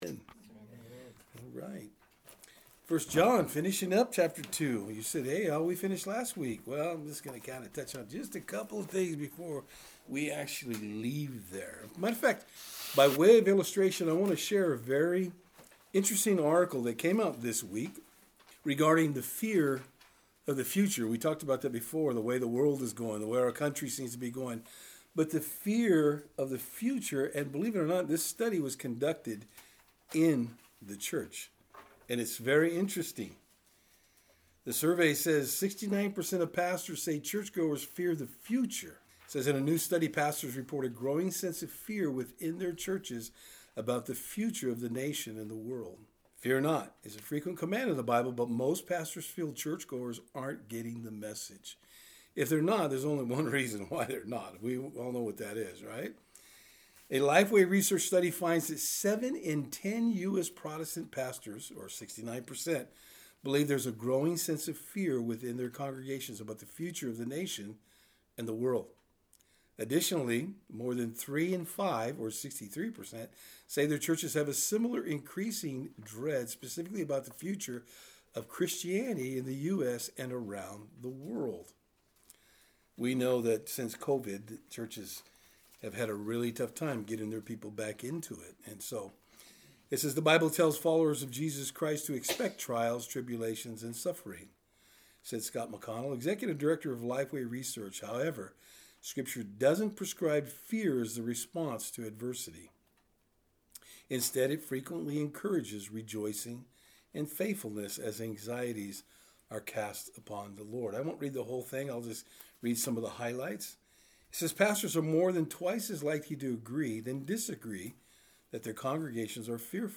1 John 2:28-3:3 Service Type: Thursday Eveing Studies In our study today we will be looking at what it means to “Abide in God.”